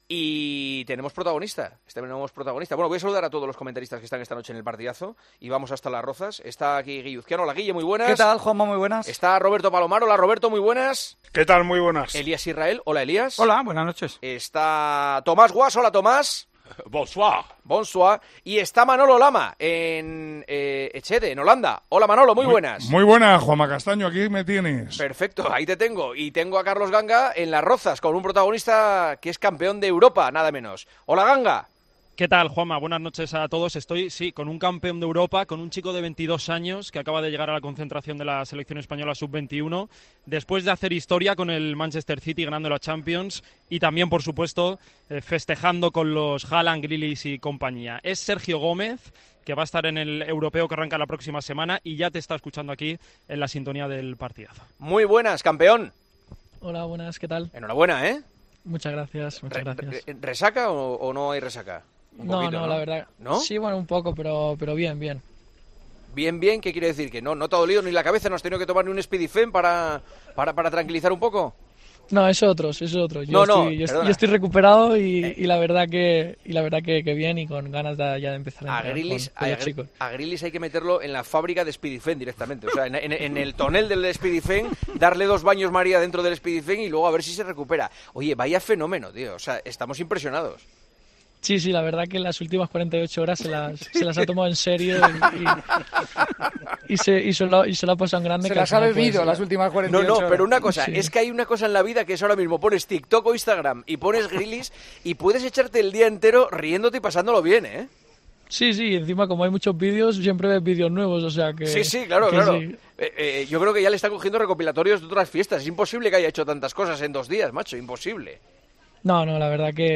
El jugador del Manchester City y de la Selección sub-21 habló con Juanma Castaño en 'El Partidazo de COPE' antes del Europeo sub-21: "Agradezco que me dejasen estar con mi club".